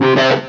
1 channel
guit1.mp3